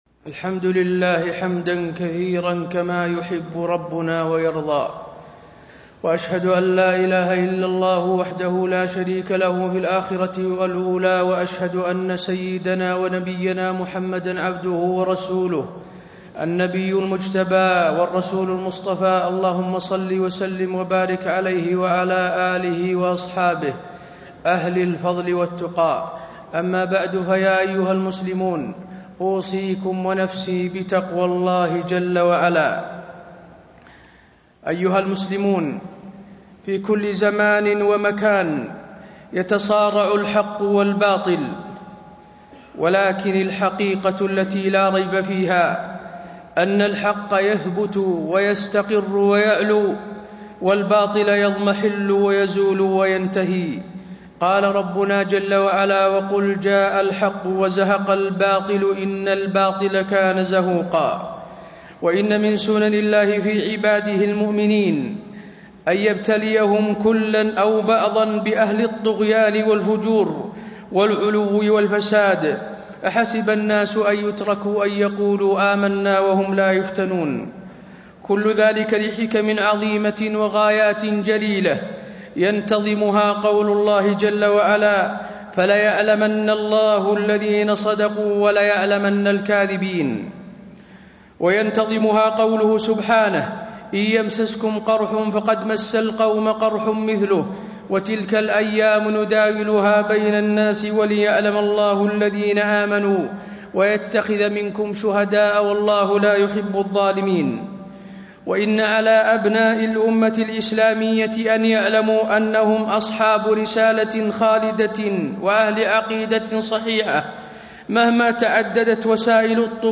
تاريخ النشر ٥ ذو القعدة ١٤٣٣ هـ المكان: المسجد النبوي الشيخ: فضيلة الشيخ د. حسين بن عبدالعزيز آل الشيخ فضيلة الشيخ د. حسين بن عبدالعزيز آل الشيخ الدفاع عن الرسول صلى الله عليه وسلم